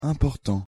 Nasal
“im” + consonantimportantɛ̃pɔʀtɑ̃
important-prononciation.mp3